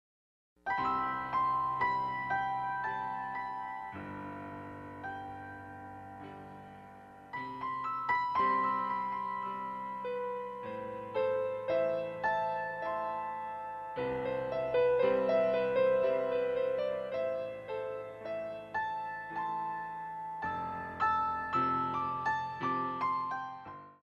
34 Piano Selections